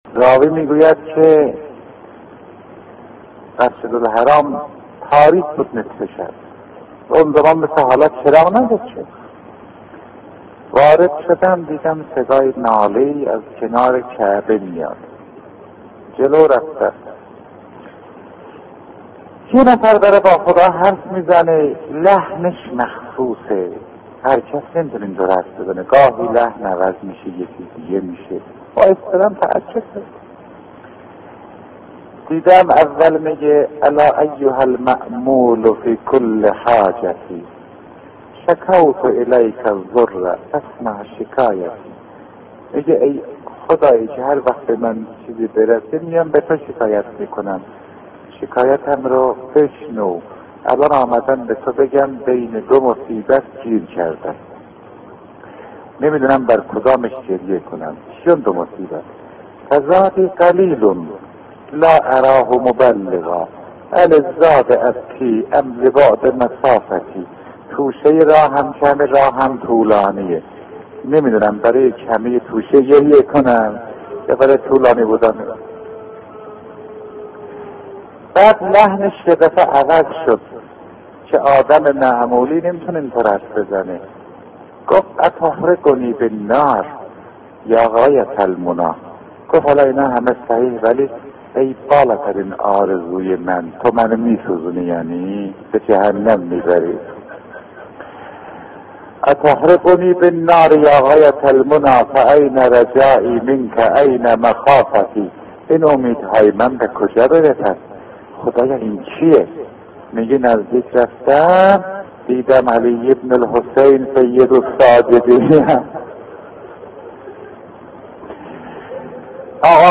روضه استاد فاطمی نیا برای امام حسین علیه السلام از زبان امام سجاد (ع)